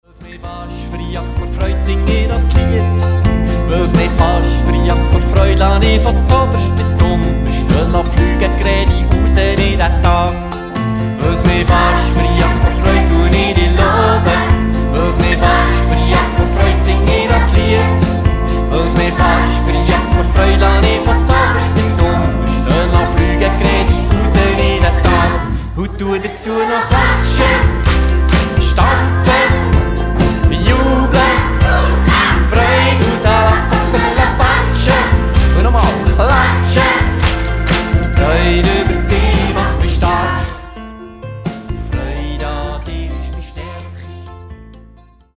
Und natürlich machen wieder Kinder mit!